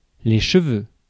Ääntäminen
Ääntäminen France (Paris): IPA: [le ʃø.vø] Tuntematon aksentti: IPA: [ʃəˈvø] IPA: /ʃfø/ Haettu sana löytyi näillä lähdekielillä: ranska Käännös Ääninäyte Substantiivit 1. hair UK US 2. poll Suku: m .